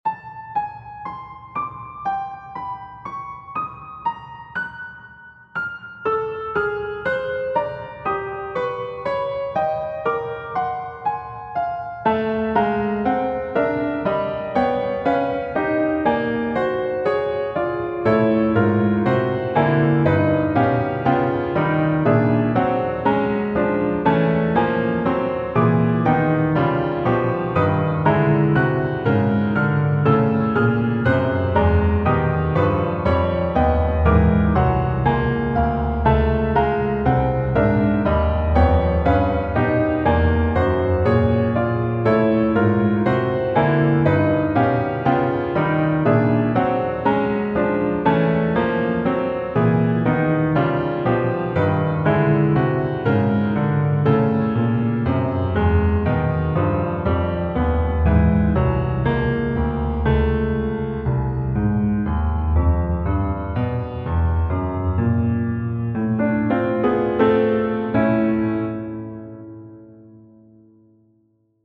Dodecaphonic Perpetual Canon for String Quartet.
This canon is based on each of the four contrapuntal transformations of a single dodecaphonic series, initially highlighted in colours corresponding to each of the 12 notes of the tone row.